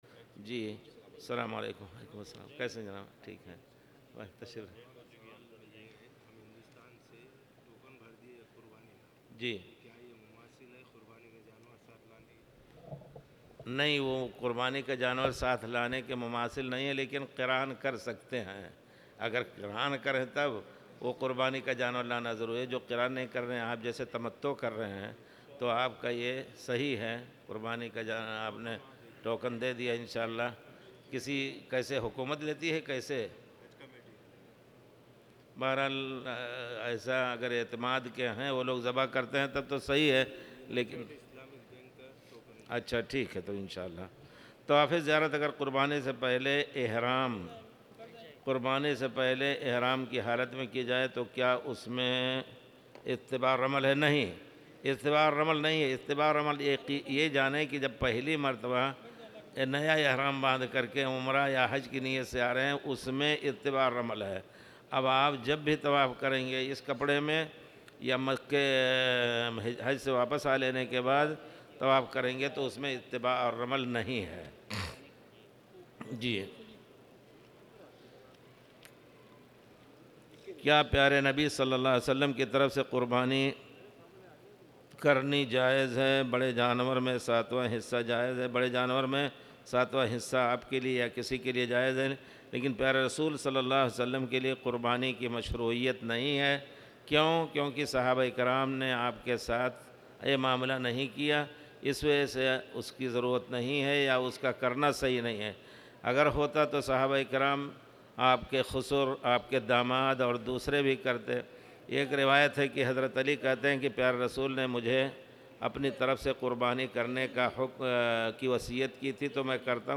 تاريخ النشر ٣ ذو الحجة ١٤٣٨ هـ المكان: المسجد الحرام الشيخ